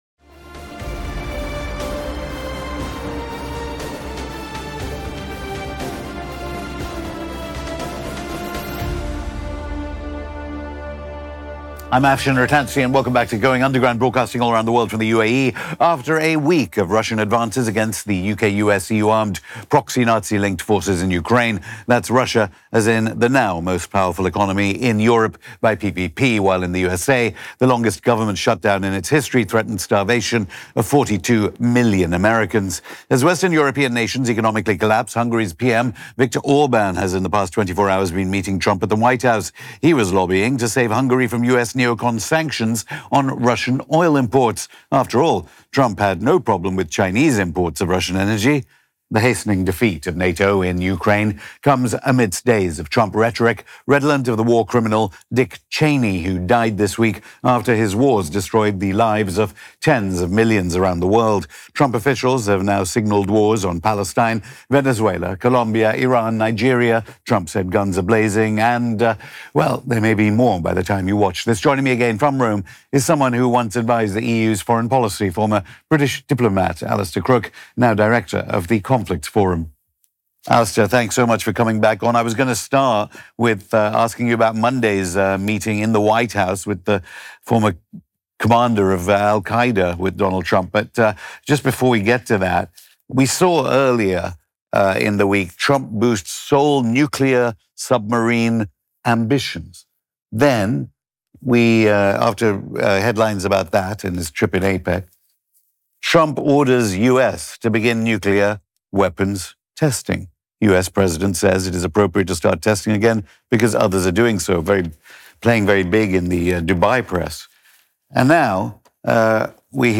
On this episode of Going Underground, we speak to Alastair Crooke, Former Middle East Advisor to the EU Foreign Policy Chief.